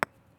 concrete4.wav